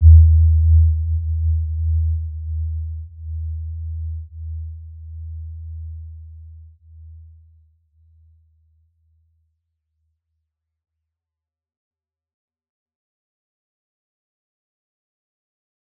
Warm-Bounce-E2-p.wav